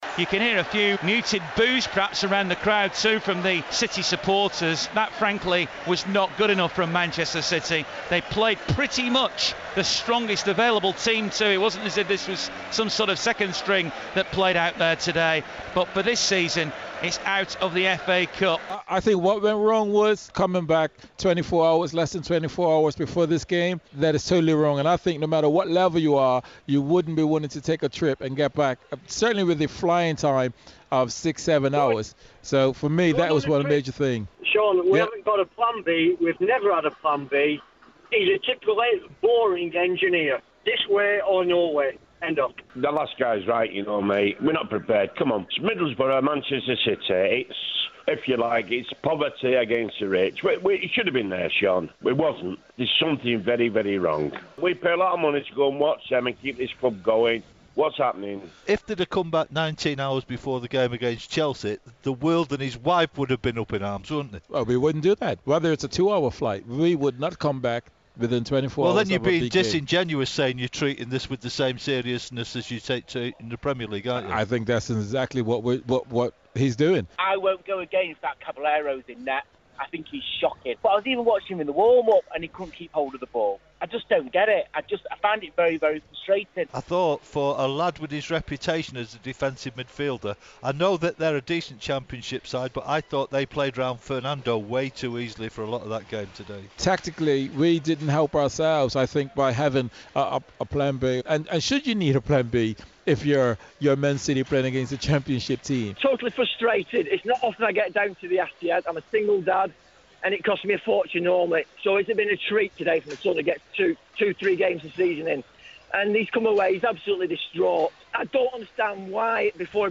Manchester City fan views on the FA Cup defeat by Middlesbrough